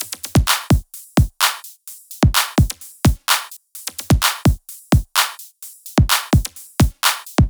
VFH1 128BPM Northwood Kit 4.wav